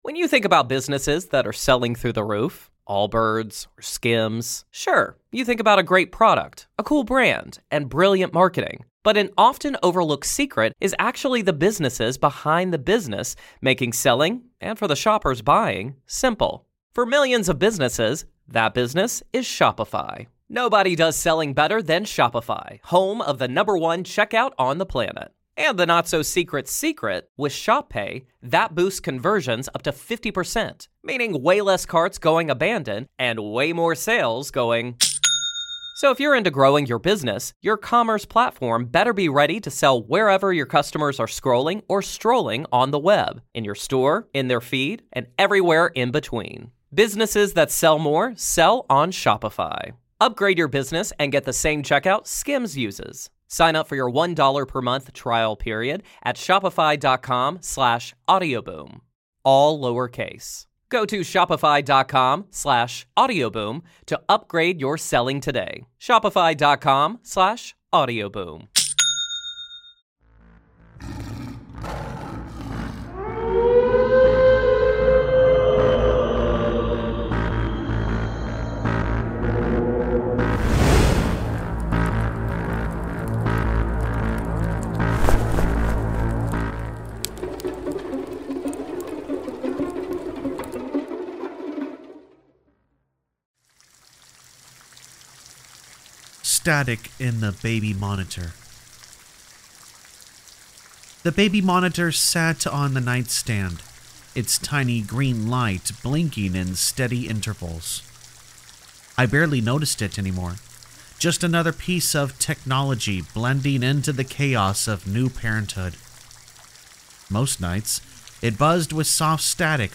Campfire Tales is a channel that is human voiced that does NOT use a fake Ai voiced simulator program. This channel is focused on Allegedly True Scary Stories and Creepypastas.